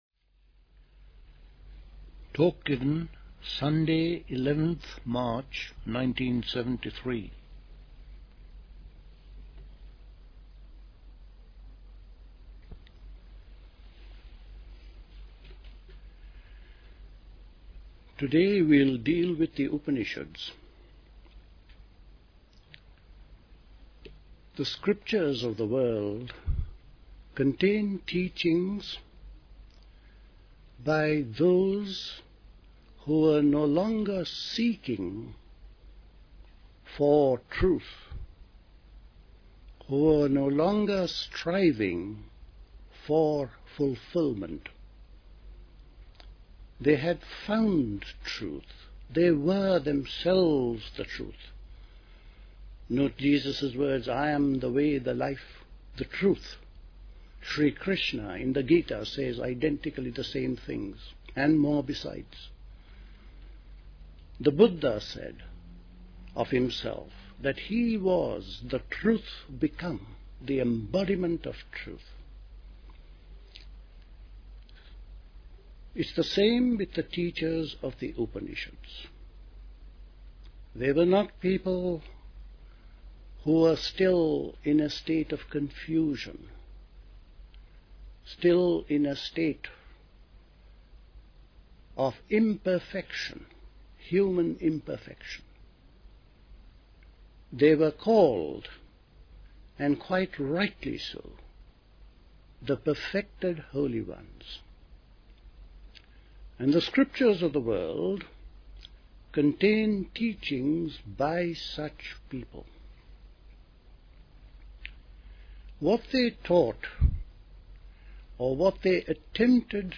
A talk
at Dilkusha, Forest Hill, London on 11th March 1973